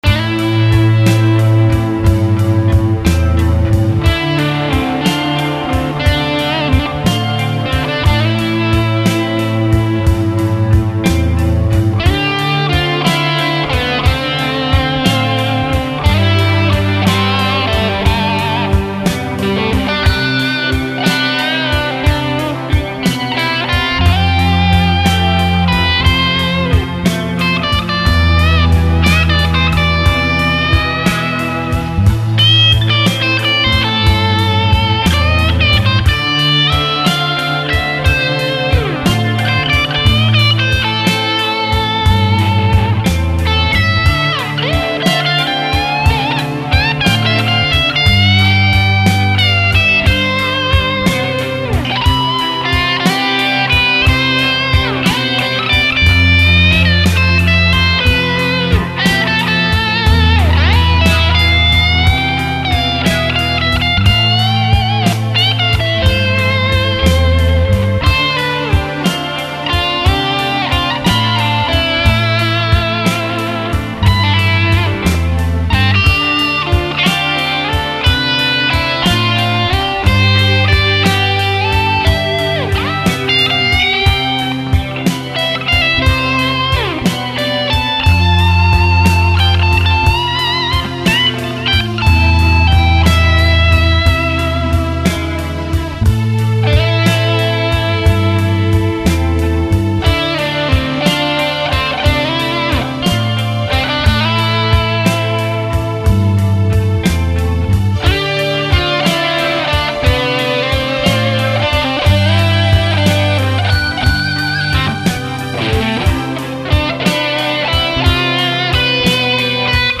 Eingespielt mit der Junior
Ich habe es mal in A-moll probiert.